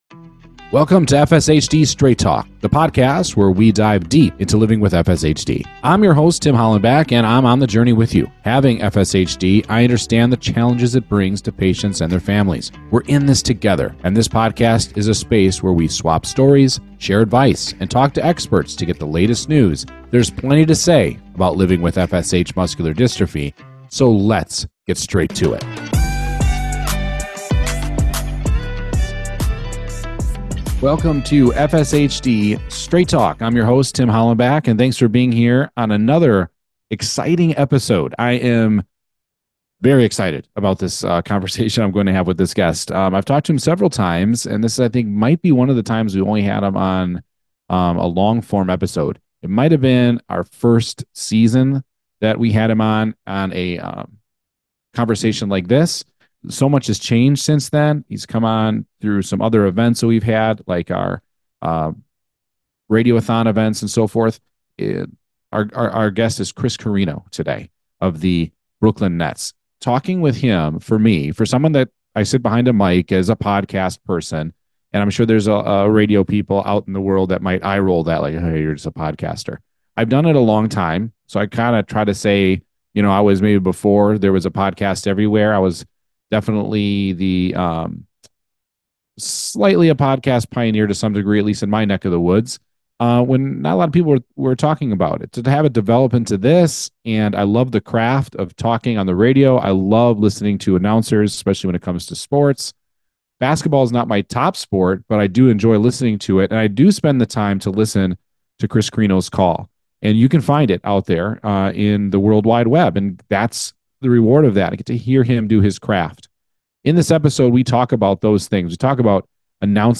He also discusses life with FSHD and advocacy work, including a recent trip to Capitol Hill. This is a slam dunk interview you don’t want to miss!